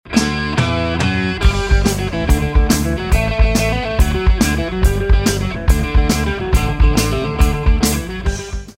exemple 2 : gallows1 ici il s'agit d'une mini-maquette que j'ai faite pour des copains ; ils voulaient un son très brut à l'ancienne; il n'y a pratiquement pas de reverbe, un peu de delay et c'est tout; mais malgré tout l'organisation de l'espace me semble cohérent; j'ai fait deux prises de guitares consécutives...